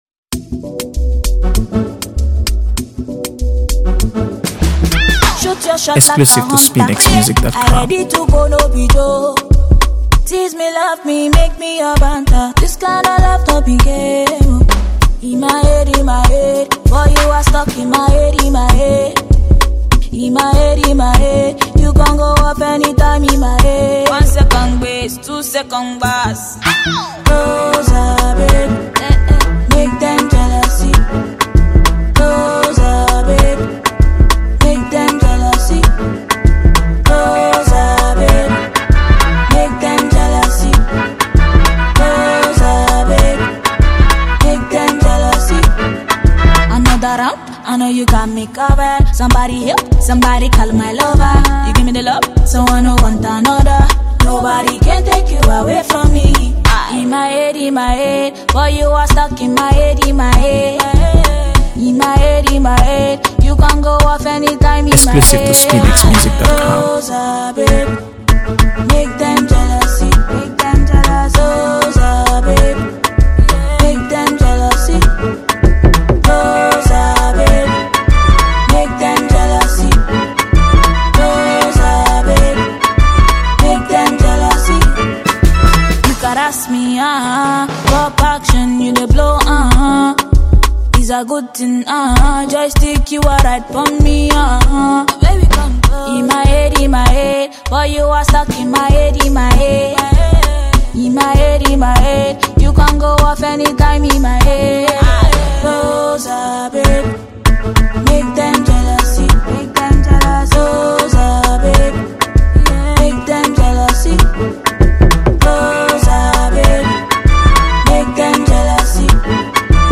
AfroBeats | AfroBeats songs
Gifted Nigerian vocalist
captivating vocals, and memorable hooks.